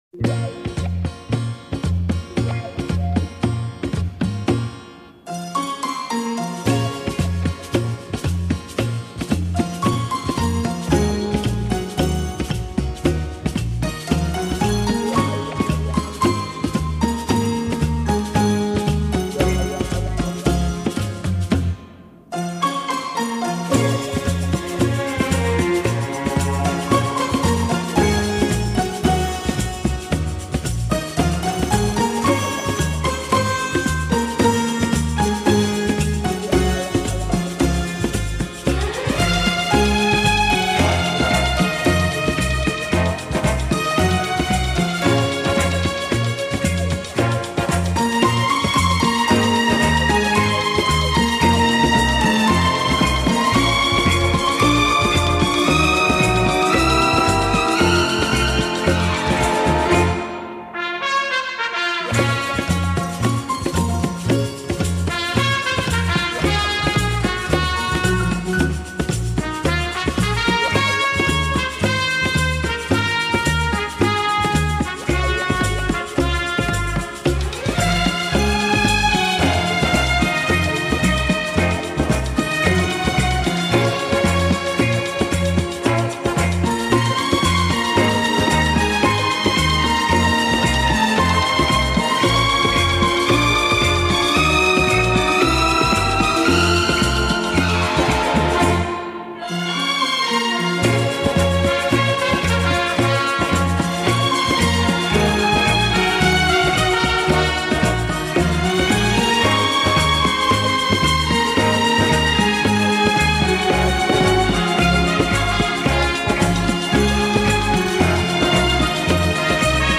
Genres:Easy Listening